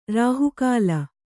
♪ rāhu kāla